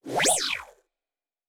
Sci-Fi Sounds / Movement / Synth Whoosh 2_2.wav
Synth Whoosh 2_2.wav